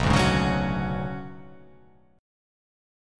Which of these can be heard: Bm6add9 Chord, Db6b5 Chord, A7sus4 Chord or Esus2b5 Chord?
Bm6add9 Chord